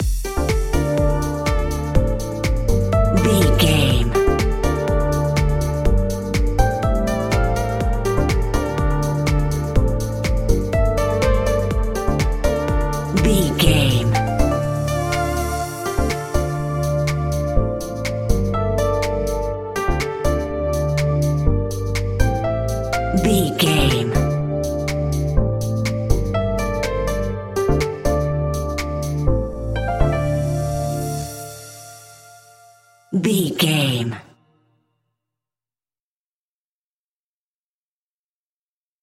Modern Chart Pop Electro Music 30 Sec.
Aeolian/Minor
groovy
uplifting
driving
energetic
synthesiser
drum machine
electric piano
techno
synth bass